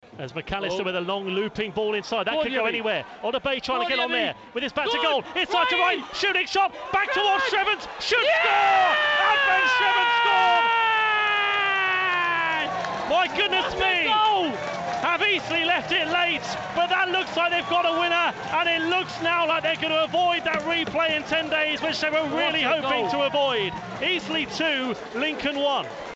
call Eastleigh's late FA Cup R1 winner